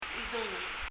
idúngel　　　[iðəŋɛl]　　　薪　　firewood
発音